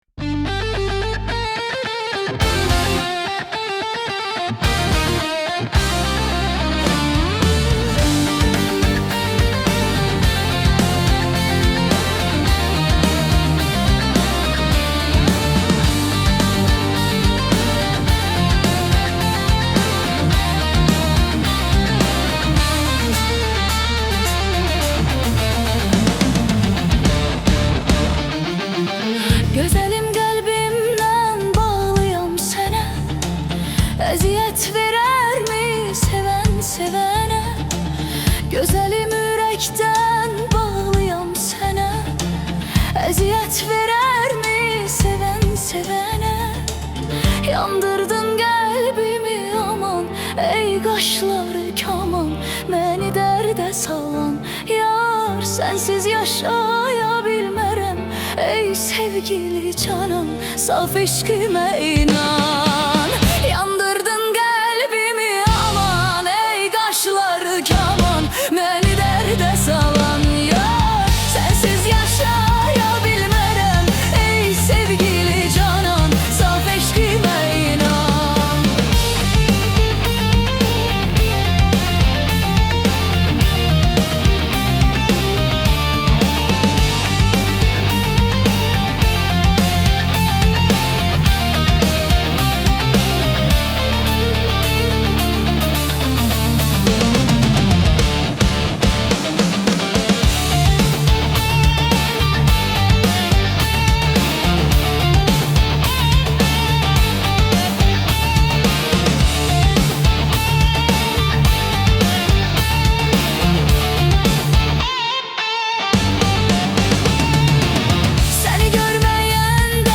Yandrdn_Kalbimi___Qlbimi_Yandrdn___Anatolian_Folk_320k.mp3